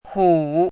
“许”读音
国际音标：ɕy˨˩˦;/xu˨˩˦
hǔ.mp3